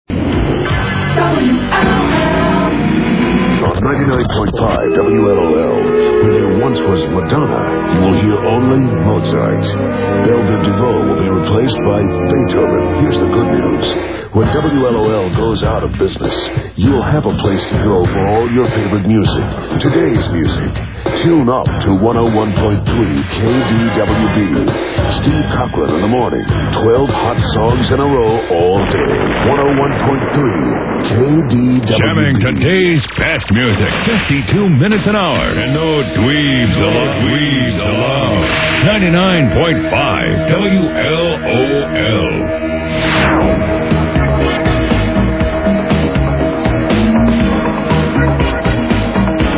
KDWB Ad (MP3)